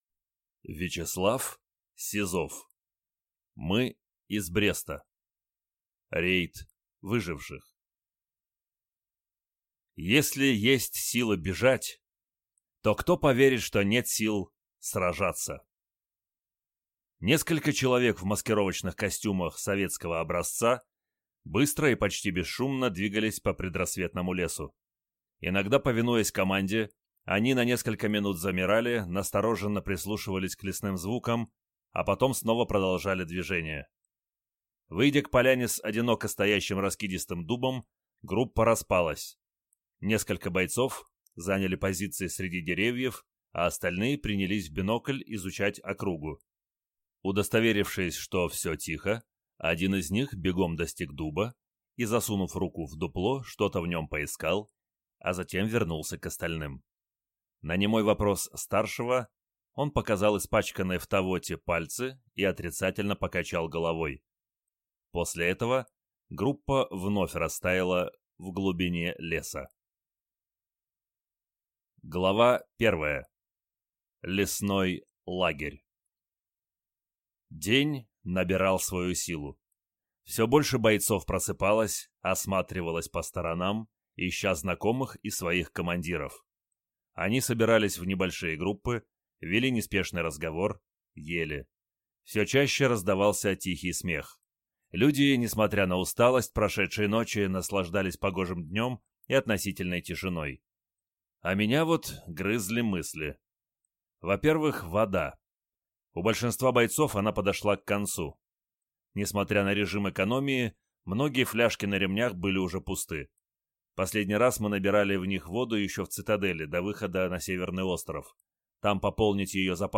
Аудиокнига Мы из Бреста. Рейд выживших | Библиотека аудиокниг